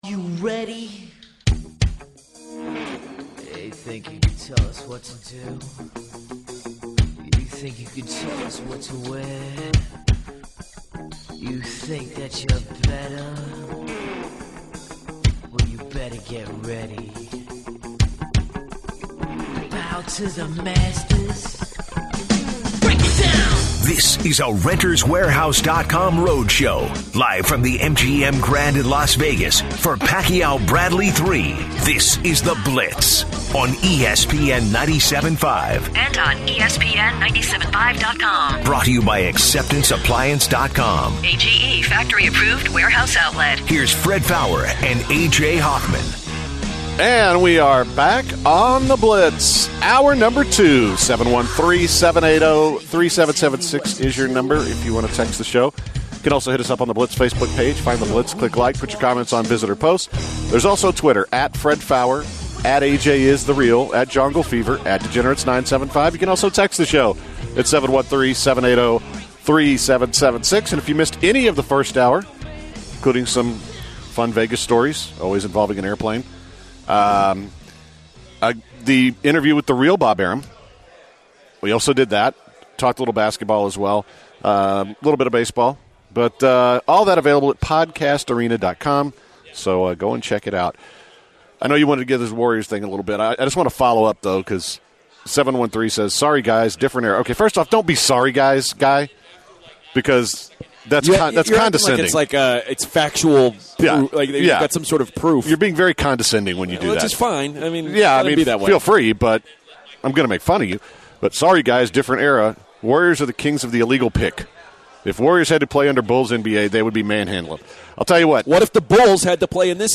continue live from Las Vegas, NV and start off with comparing the Golden State Warriors and the Chicago Bulls. An update from the Astros game as fans continue to dress up, appearing as dinosaurs and dragons today. The guys advise Jay Wright and John Calipari to ignore calls from NBA teams and questions the chances of the Rockets making the playoffs.